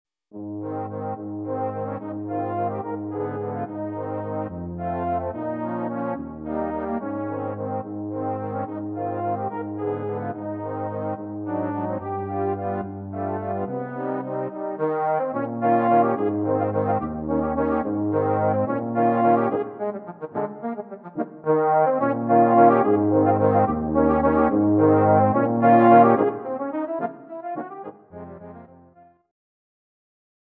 【編成】トロンボーン四重奏（3 Tenor Trombone and Bass Trombone）
の４曲をトロンボーン４重奏に編曲しました。